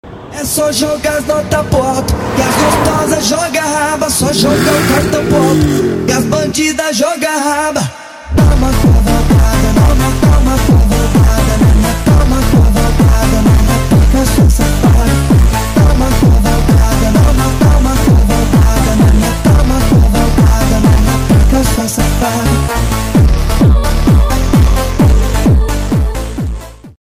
Has a performance pack V8 5.0l engine with 435 horsepower manual 6 speed (MT82 gearbox) And Equipment version (Base) This is an American Dream, I love this car🤍😎My car and